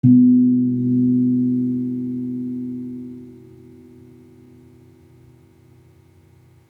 Gamelan Sound Bank
Gong-B2-p.wav